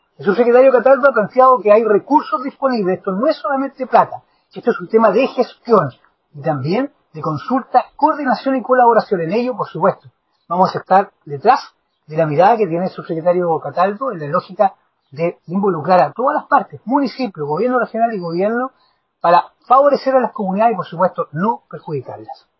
Al respecto, el presidente de la Comisión de Medio Ambiente del Consejo Regional de Los Lagos, Francisco Reyes, valoró la mirada del Subsecretario Cataldo, reiterando que se debe velar por el bienestar ciudadano y medioambiental antes de generar un nuevo proyecto para el manejo de los desechos.